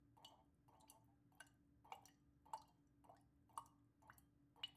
I recorded water dripping into glass last night. Here is a short sample...there is also a strange springy quality to it...
It almost looks like there is a sine wavey impulse followed by a higher pitched noisy echo when it reflects off the glass.
WaterDripGlass.aif